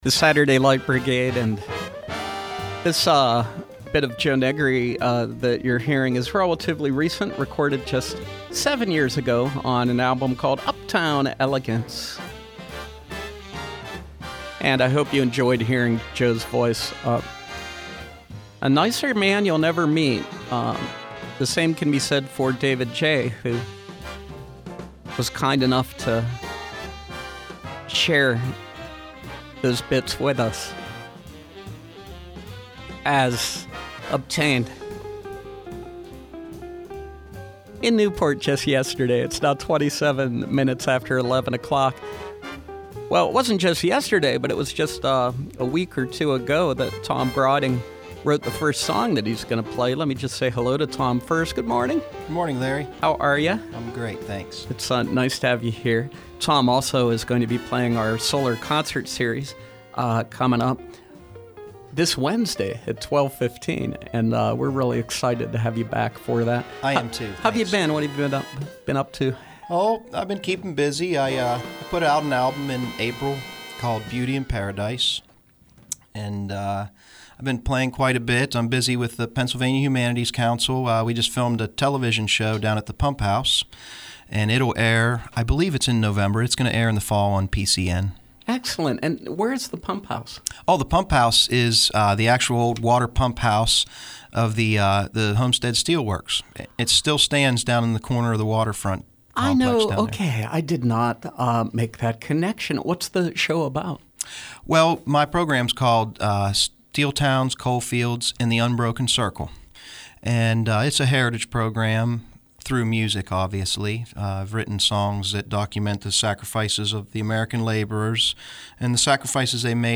perform live in our studios.